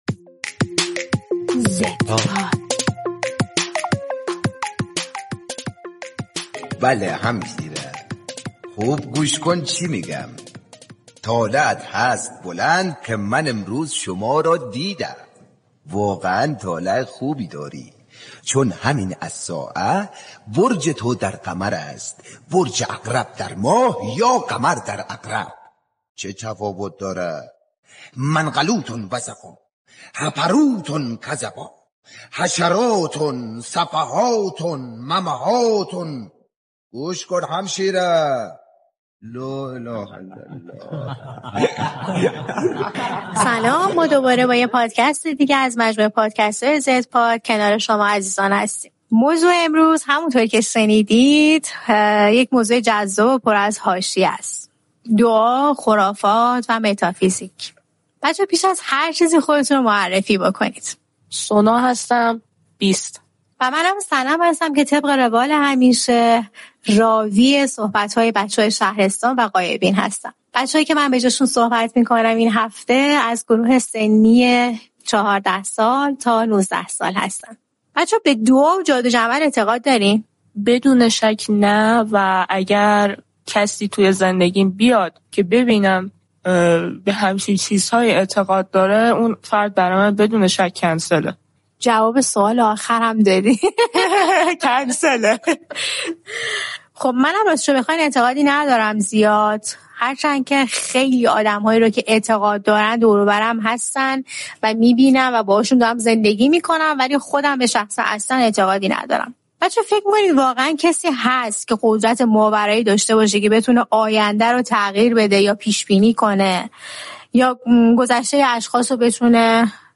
در این قسمت پادکست زدپاد، دو نفر از بچه‌های این نسل درباره خرفات، دعا، فال، رمالی و متافیزیک گفت‌وگو می‌کنند و از دیدگاه‌های این نسل درباره موضوع‌های مشابه می‌گویند.